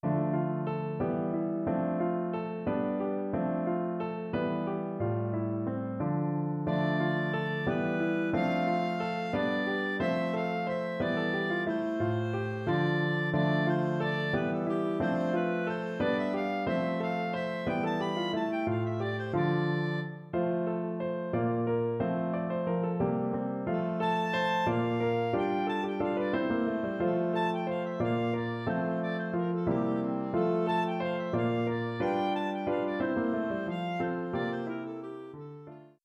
Instrumentation: Bb Clarinet, Piano